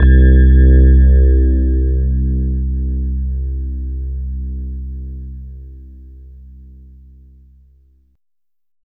20 RHOD C2-L.wav